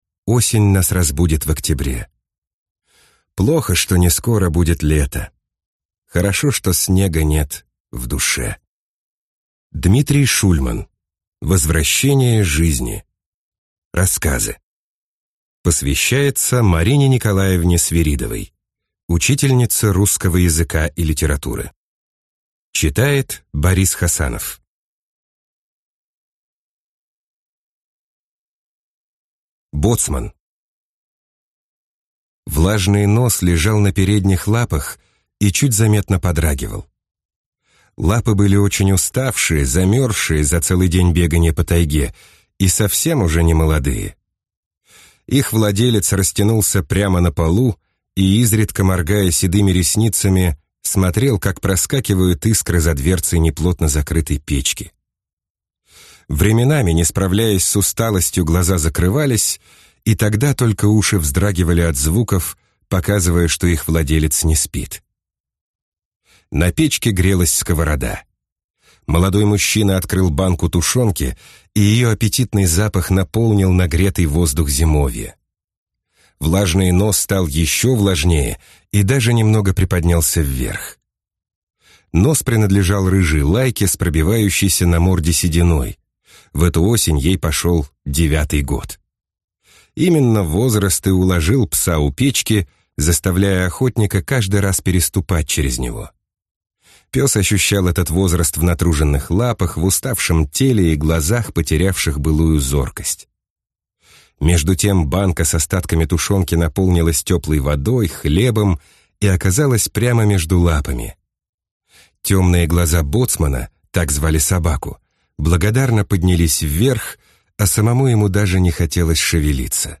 Аудиокнига Возвращение жизни | Библиотека аудиокниг